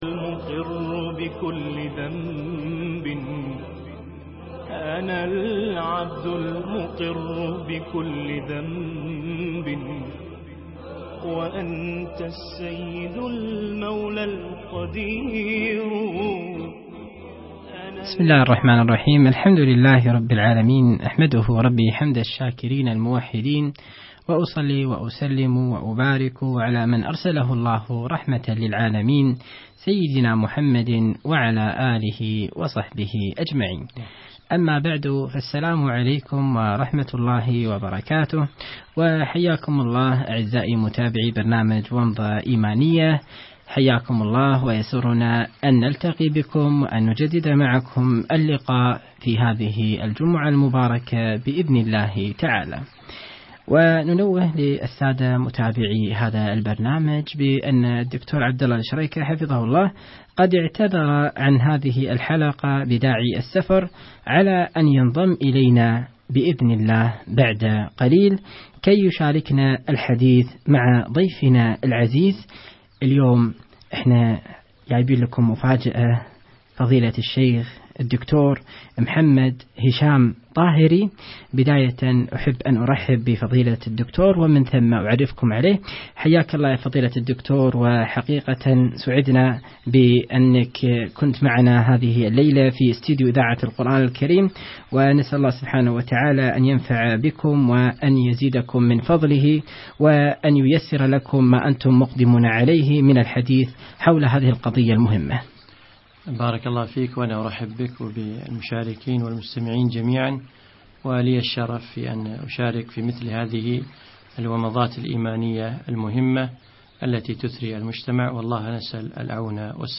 جريمة التكفير برنامج ومضة إيمانية لقاء عبر إذاعة القرآن الكريم بدولة الكويت